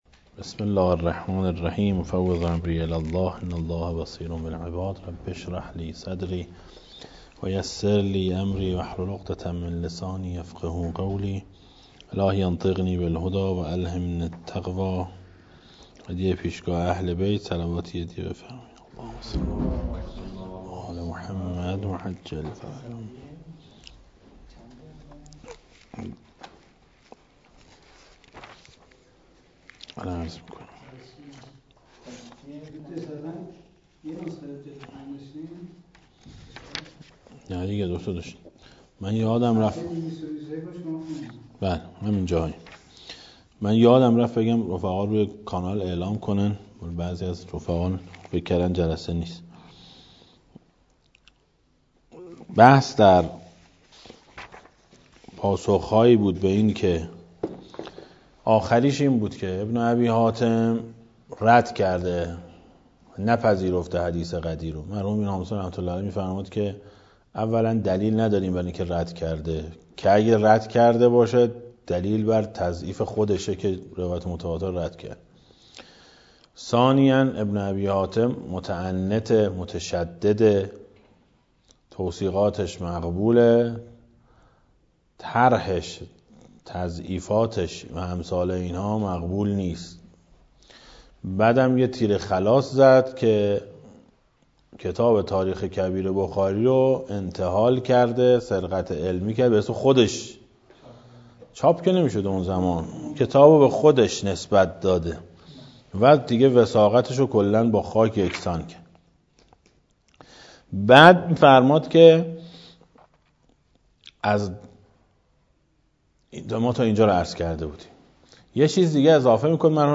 در مدرس پژوهش حوزه علمیه امام خمینی (ره) تهران برگزار گردید